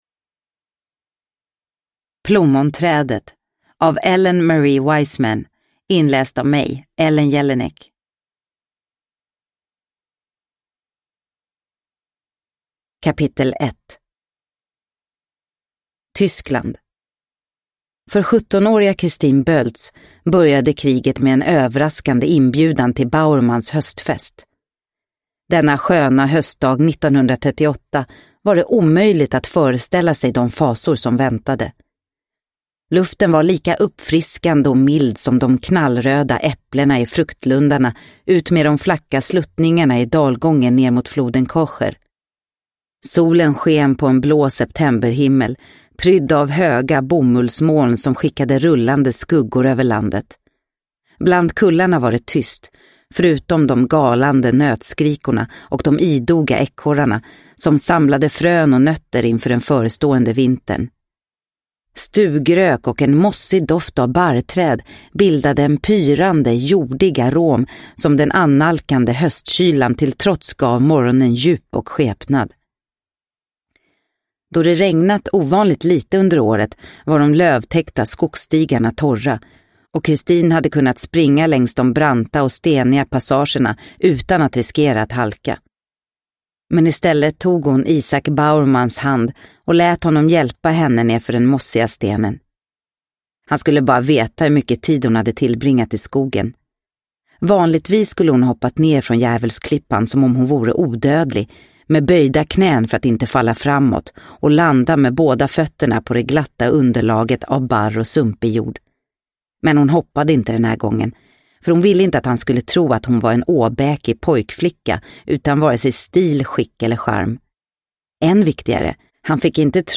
Plommonträdet – Ljudbok – Laddas ner